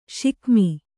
♪ śikmi